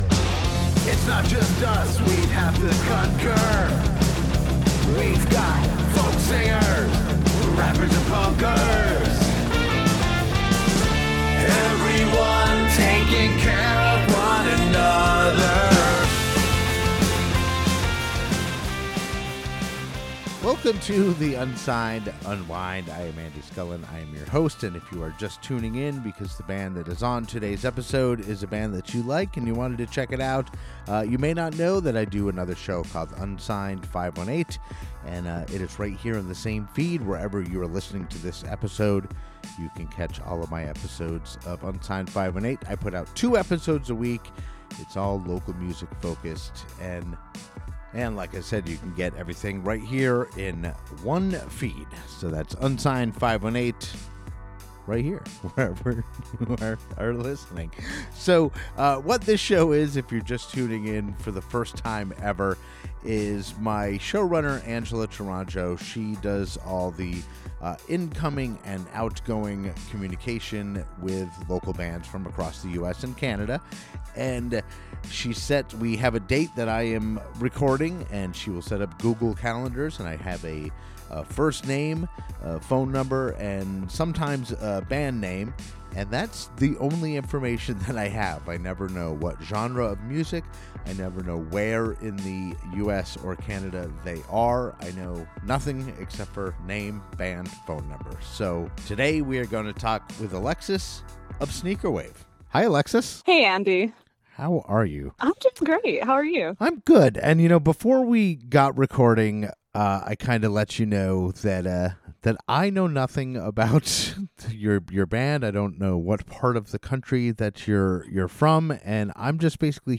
This show is an attempt to gather great local music from all over the US and Canada, have a brief conversation with the band/musician and play one of their songs. My goal is that local-music enthusiasts, such as myself, can discover great local music that otherwise may have remained hidden to them.